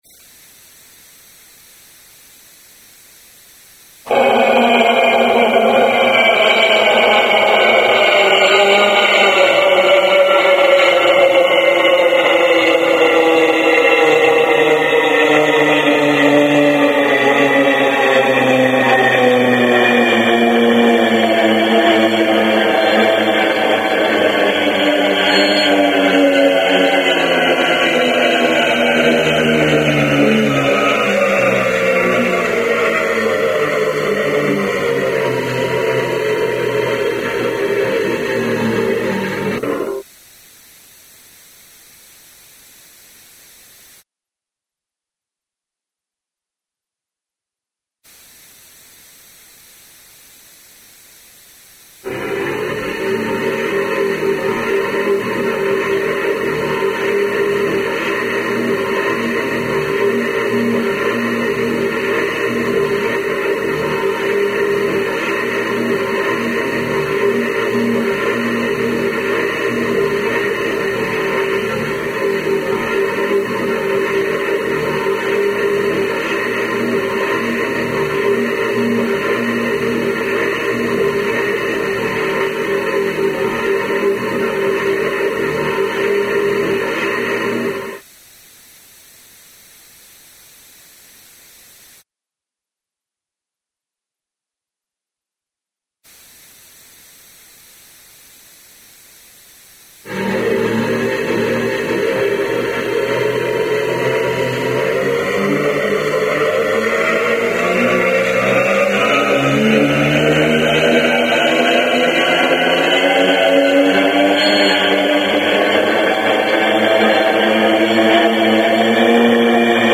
intonarumori solo and white noise
audio sketch with solo stropicciatore and white noise